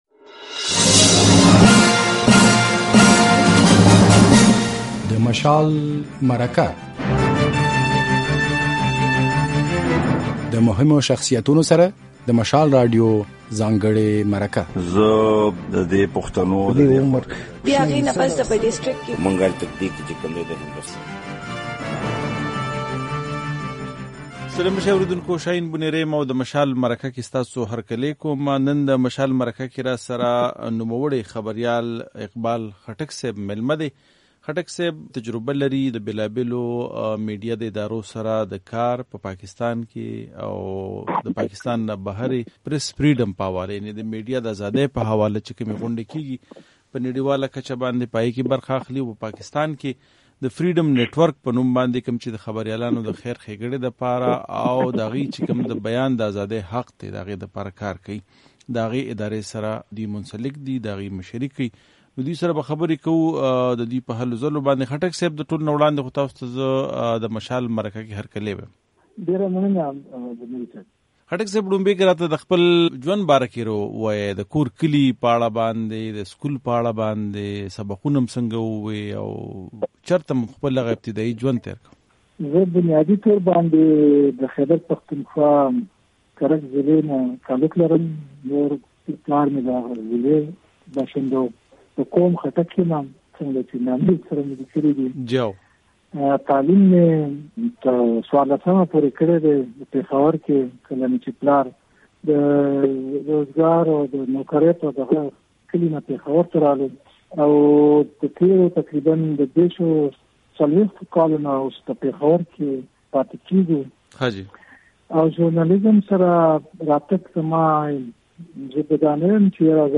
د مشال مرکه